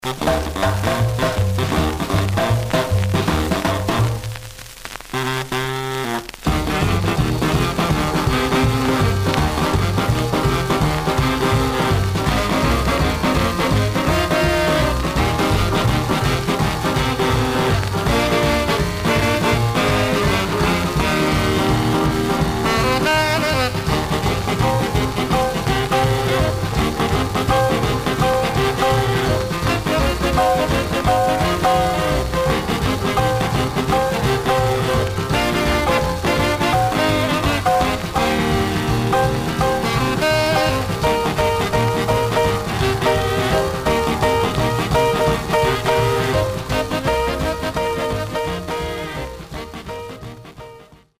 Surface noise/wear
Mono
R&B Instrumental